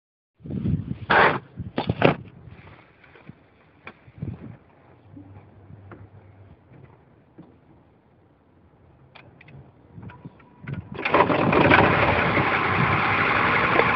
С 11 по 12 сек. грохот, запуск на холодную после 2-х суток простоя, за бортом - 15.
Тут именно грохот.